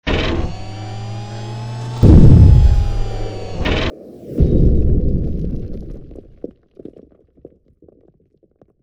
rocketgroundin.wav